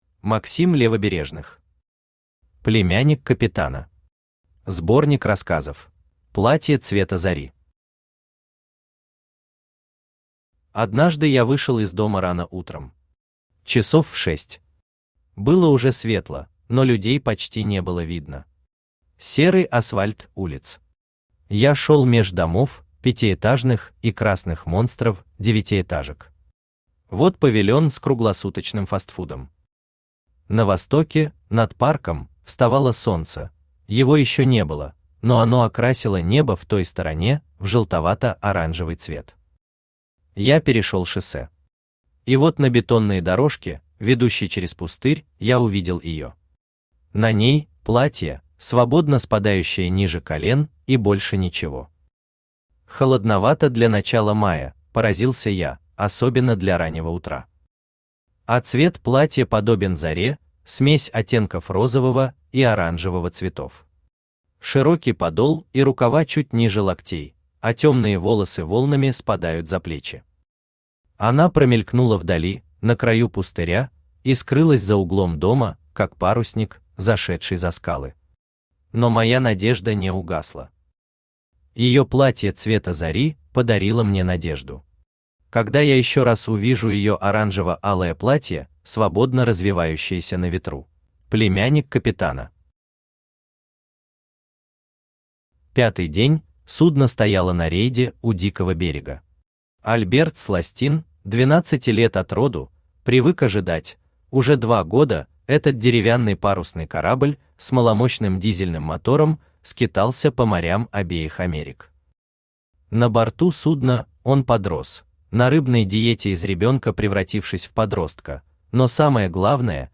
Аудиокнига Племянник капитана. Сборник рассказов | Библиотека аудиокниг
Сборник рассказов Автор Максим Левобережных Читает аудиокнигу Авточтец ЛитРес.